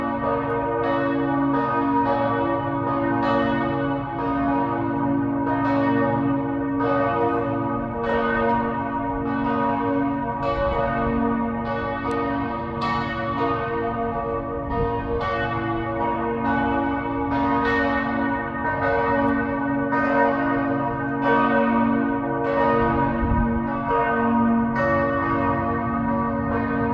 Bells, bells, bells
Some of them chime on the hour, or even on the quarter-hour, but what I find most curious is that some steeple bells peal at unexpected times and for extended periods.
The bell tower prominent in the view from our open windows belongs to Liebfrauenkirche, and has a particularly large set of bells. They ring through loud and clear despite the ETH office building in the foreground.